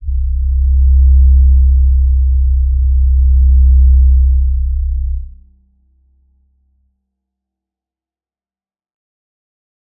G_Crystal-C2-pp.wav